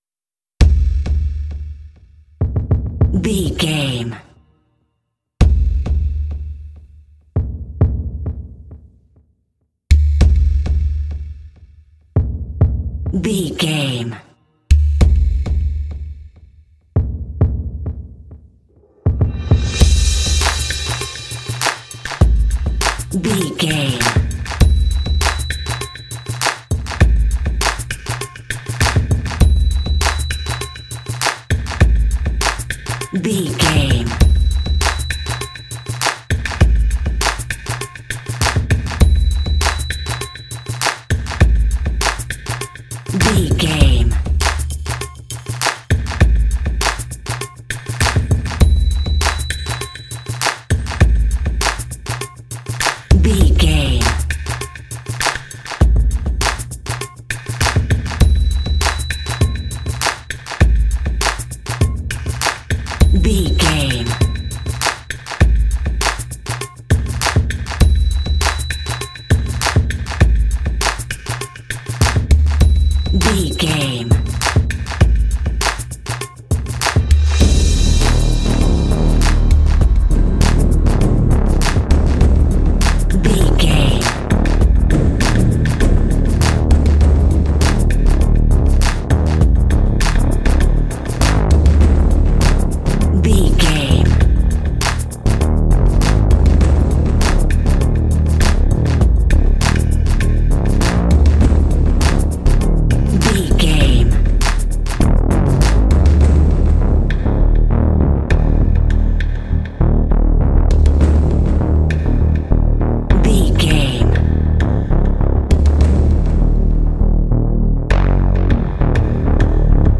Aeolian/Minor
E♭
suspense
anxious
scary
disturbing
drums
percussion
synthesiser
cinematic
underscore